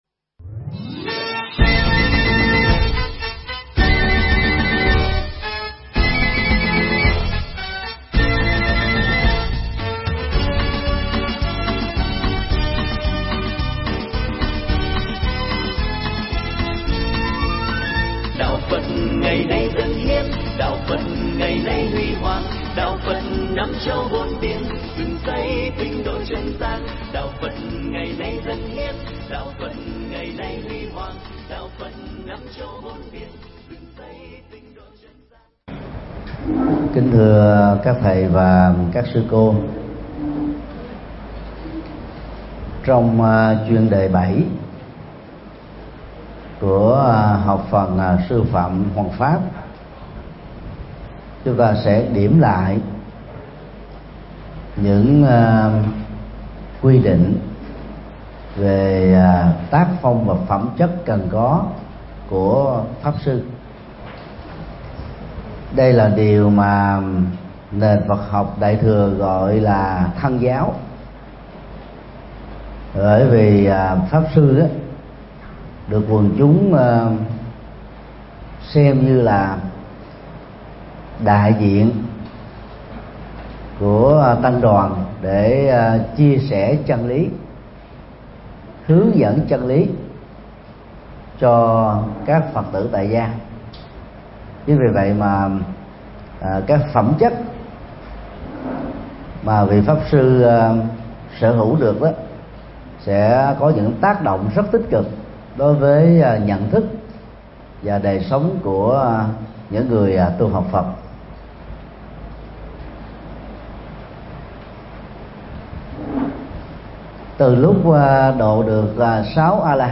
Mp3 Pháp Thoại Pháp Sư
giảng tại Học Viện PGVN cơ sở Lê Minh Xuân